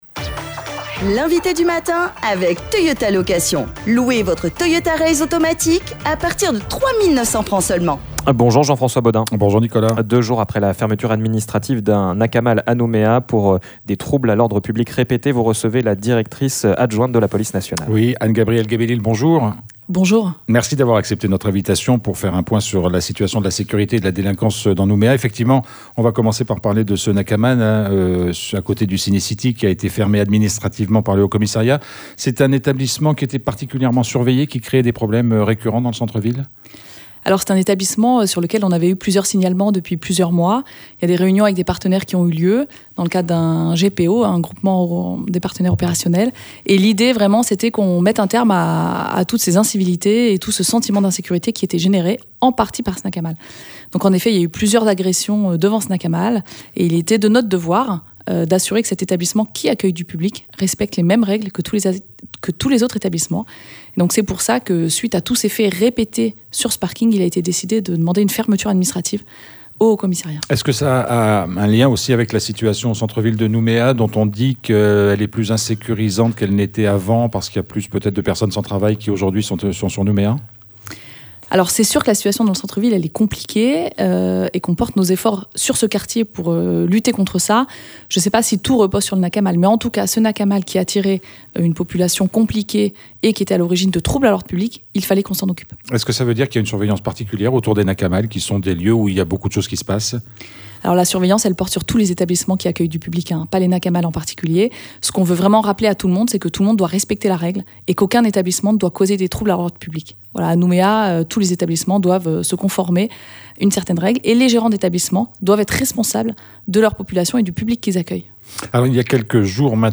Le contrôle des Nakamals, 2 jours après la fermeture administrative d’un bar à kava sur Nouméa, c'est l’un des sujets sur lesquels nous sommes revenus à 7h30 avec notre invitée. Nous étions en effet en compagnie d’Anne-Gabrielle Gay-Belille, directrice adjointe de la police nationale. L’occasion de faire aussi avec elle un point sur la sécurité et la lutte contre la délinquance à Nouméa.